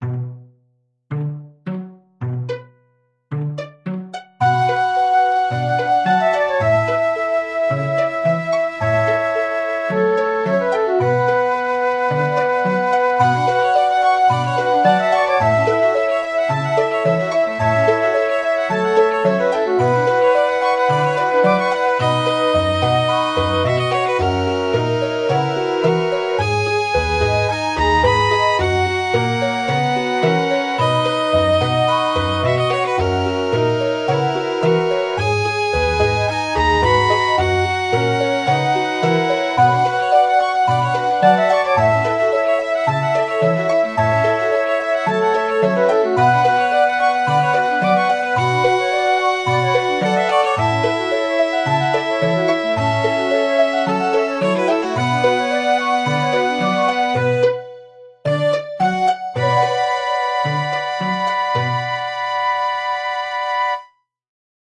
מבוצע על ידי התוכנה החינמית muscore.
ב. אהבתי את הקולות שמשתלבים, את ההבניה ההדרגתית ואז השבירה של מנגינת הנושא להתפצלות חדשה.
מתוק ומקפיץ!